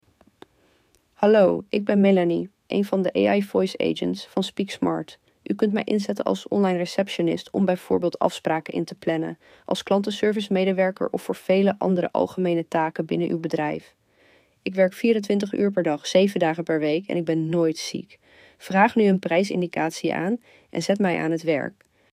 Meet your new team members ⇓
Voice AI Agents »
Melanie-intro.mp3